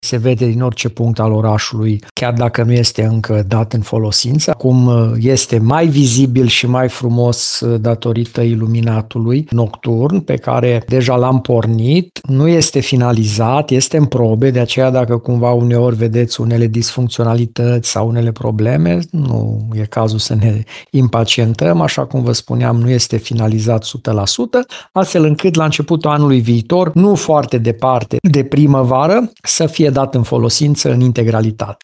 Primarul municipiului Arad, Călin Bibarț, spune că iluminatul este deocamdată în probe.